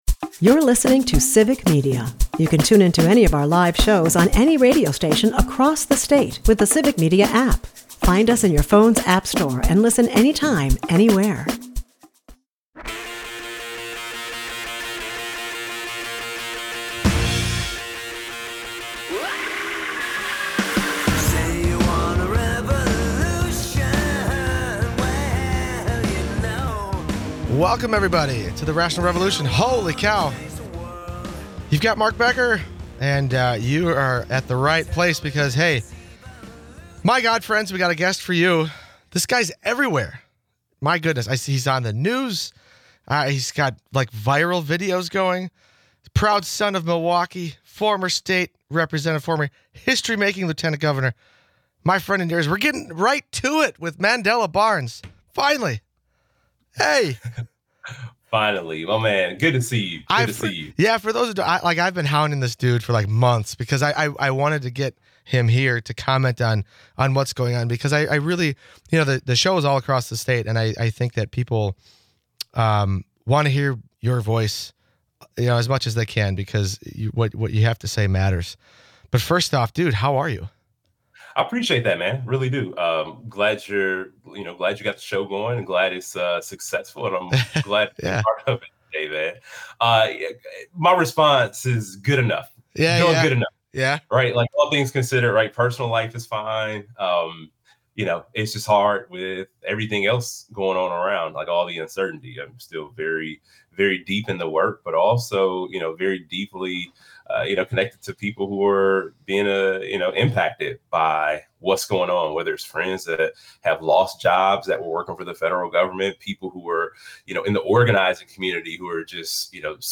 rational revolution What’s Next with Mandela Barnes Guests: Mandela Barnes